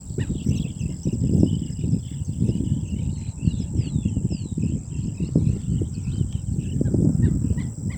Playerito Pectoral (Calidris melanotos)
Nombre en inglés: Pectoral Sandpiper
Condición: Silvestre
Certeza: Fotografiada, Vocalización Grabada